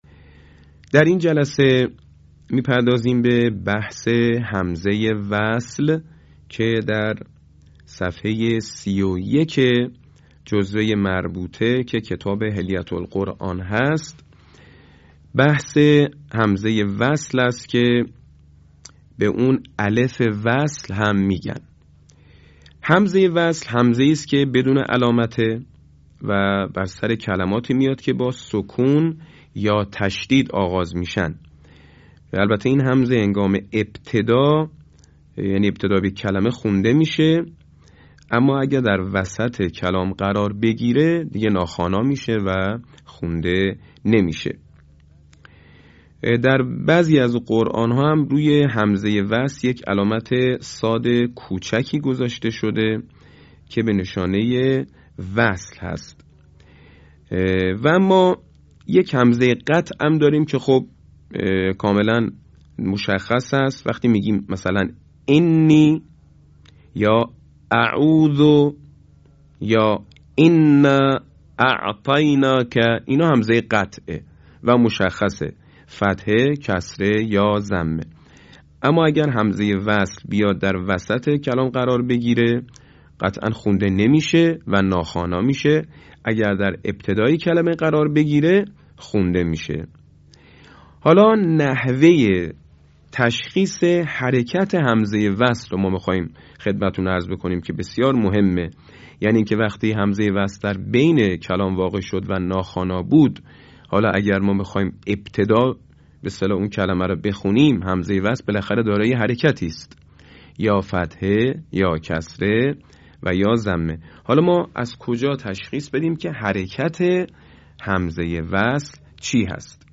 صوت | آموزش تجوید همزه وصل
آموزش تجوید